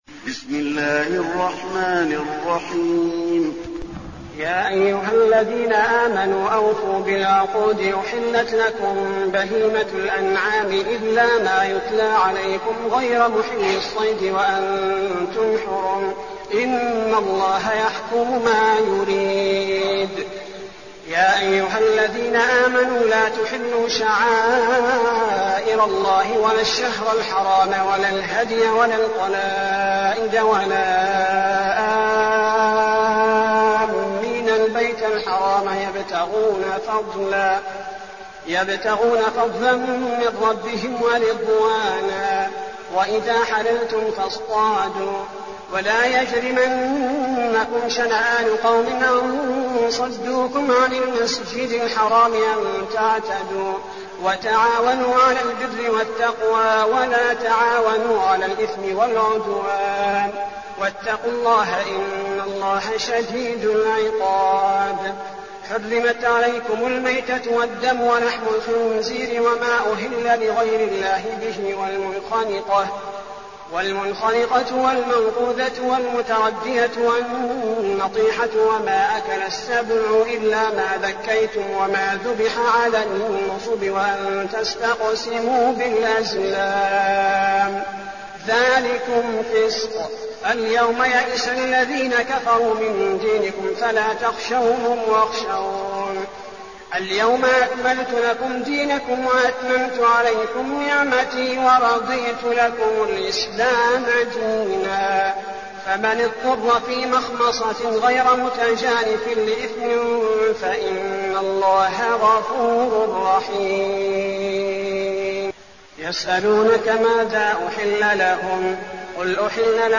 المكان: المسجد النبوي المائدة